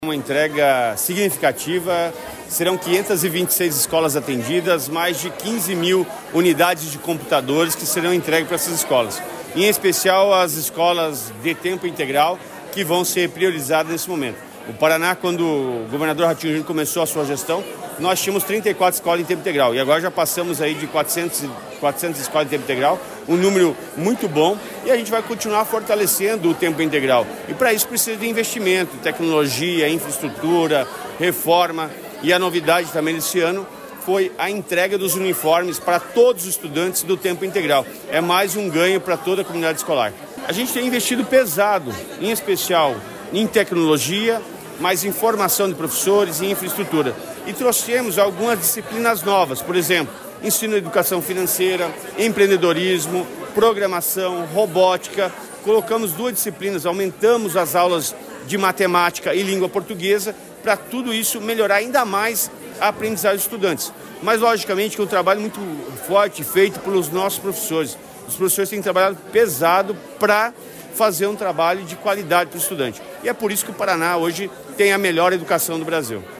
Sonora do secretário da Educação, Roni Miranda, sobre a entrega de 15 mil desktops para 526 escolas